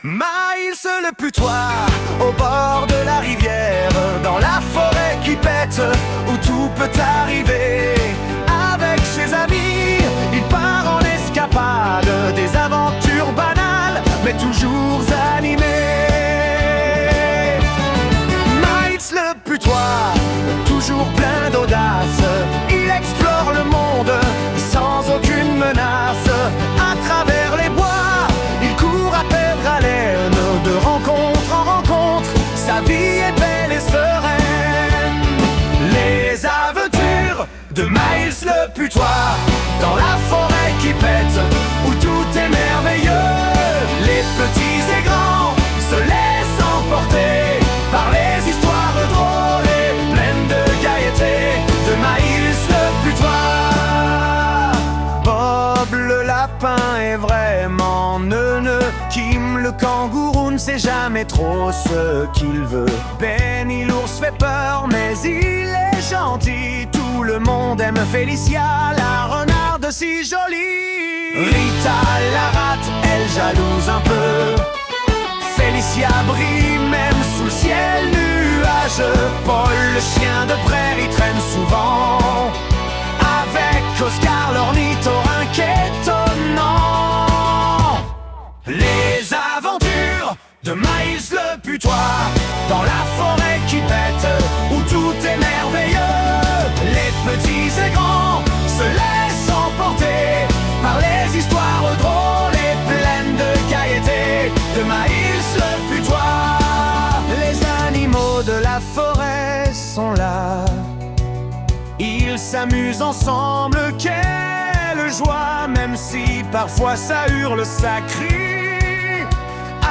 Générique
Mais Myles a gardé une démo de ce qu'aurait dû être la chanson.
suno_ai_-_les_aventures_de_myles_le_putois.mp3